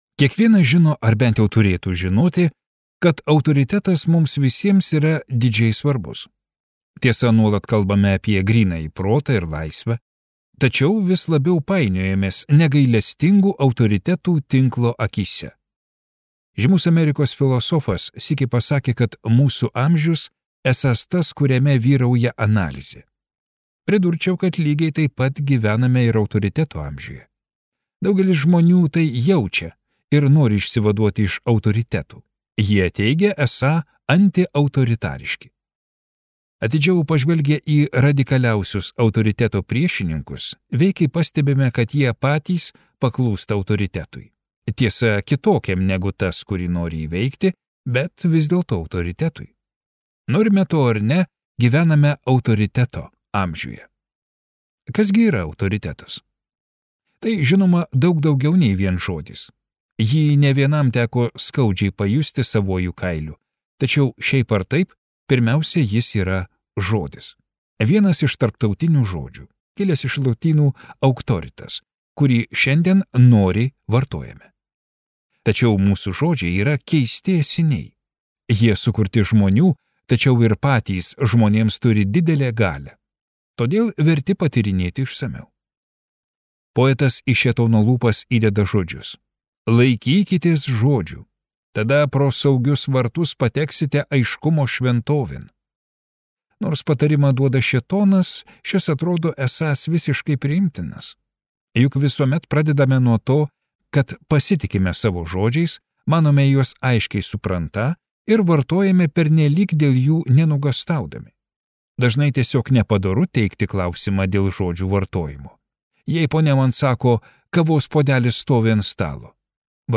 Skaityti ištrauką play 00:00 Share on Facebook Share on Twitter Share on Pinterest Audio Kas yra autoritetas?